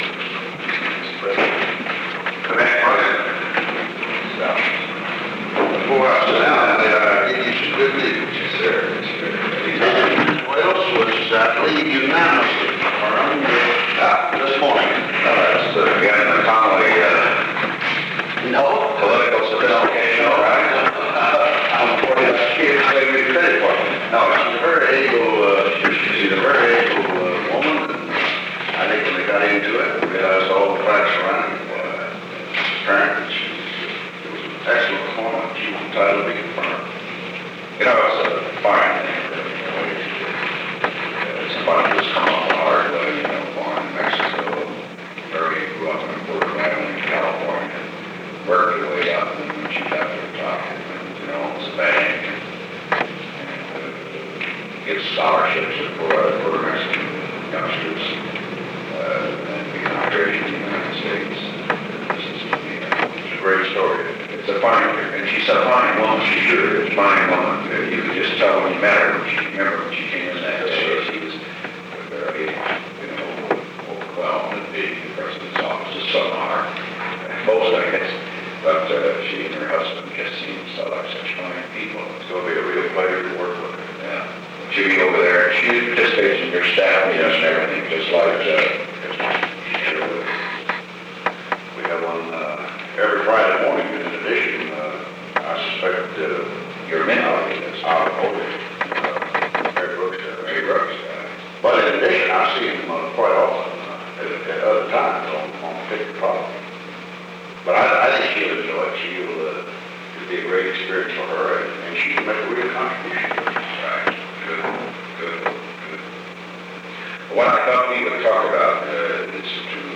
Recording Device: Oval Office
The Oval Office taping system captured this recording, which is known as Conversation 630-003 of the White House Tapes.